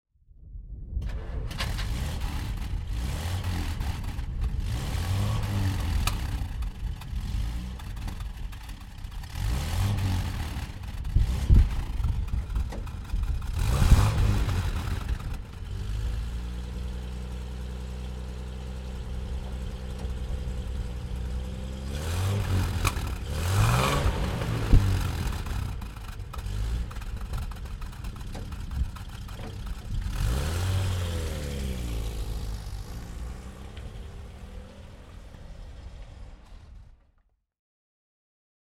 Motorsounds und Tonaufnahmen zu Bentley Fahrzeugen (zufällige Auswahl)
Bentley R-Type James Young Two Door Saloon (1953) - Starten und Leerlauf